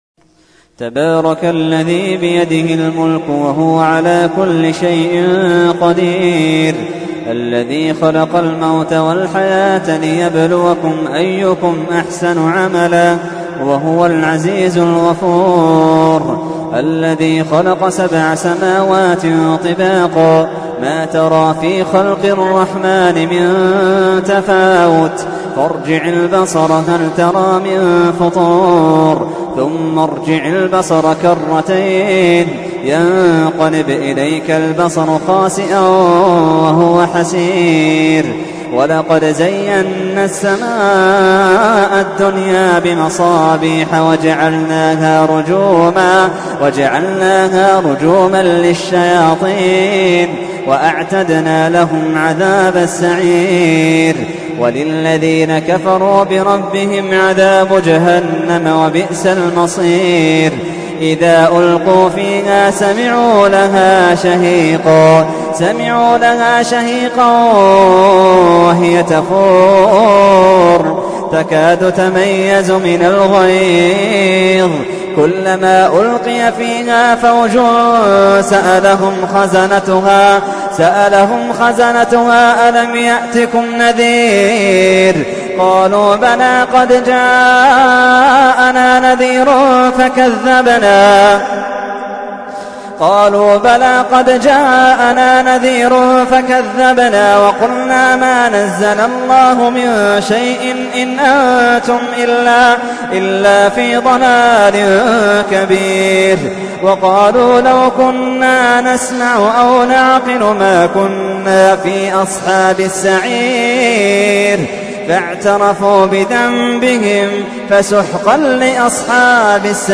تحميل : 67. سورة الملك / القارئ محمد اللحيدان / القرآن الكريم / موقع يا حسين